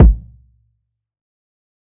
TC Kick 02.wav